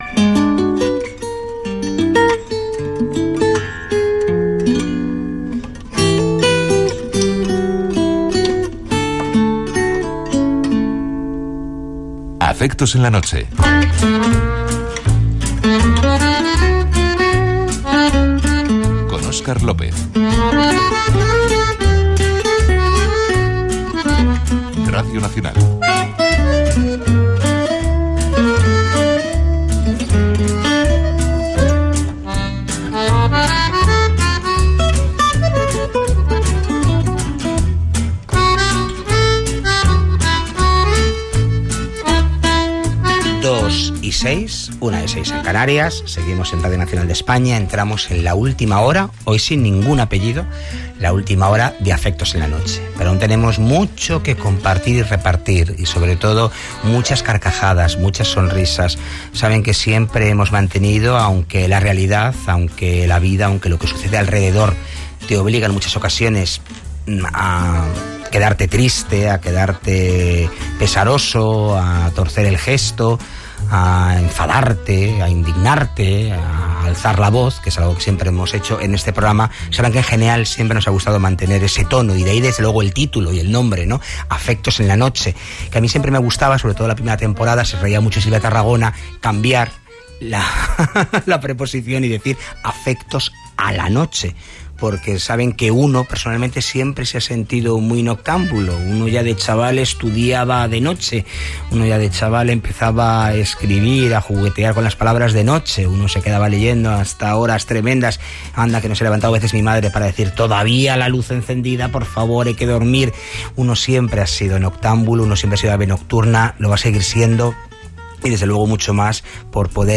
Indiactiu del programa, hora, presentació de la tercera hora en l'última edició del programa i tema musical
Entreteniment